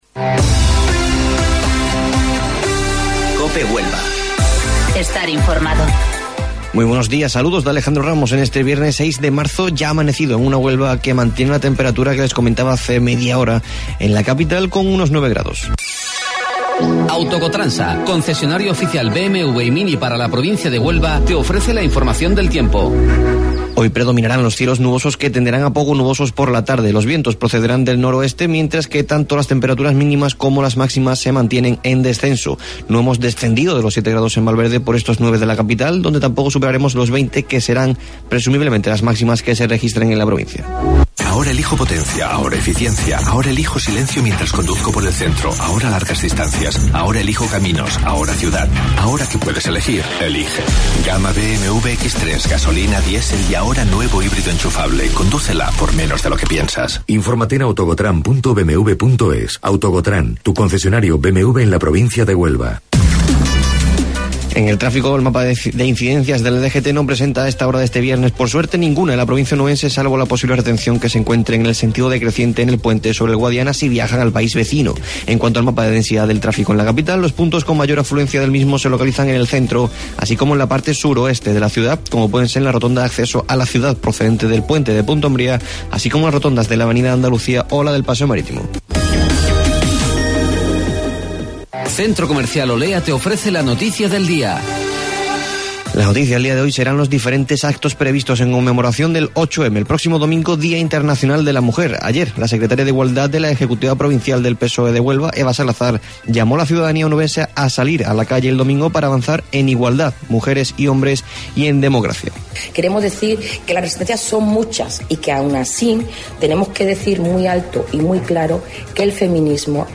AUDIO: Informativo Local 08:25 del 6 Marzo